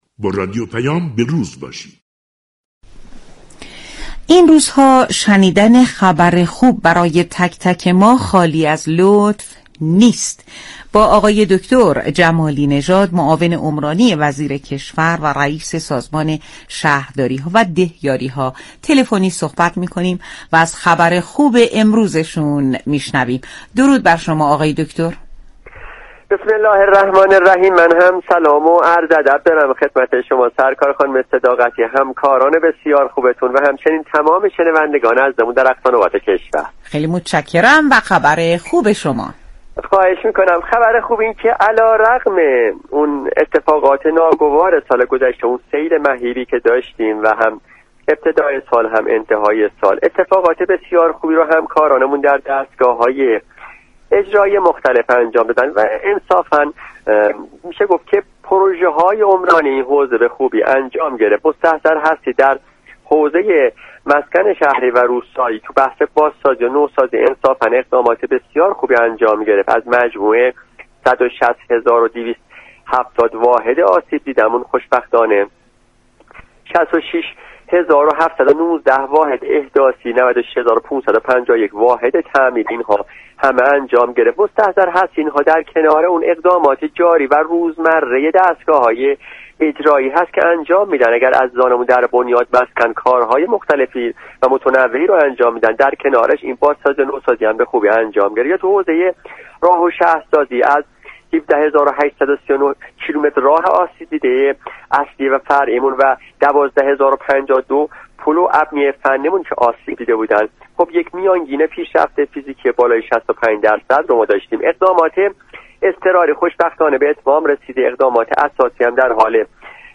جمالی نژاد ، معاون عمرانی وزیر كشور و رئیس سازمان شهرداریها و دهیاریها ، در گفتگو با رادیو پیام ، جزئیاتی از پروژه‌های عمرانی بازسازی مناطق آسیب دیده از سیل سال گذشته در كشور را بازگو كرد .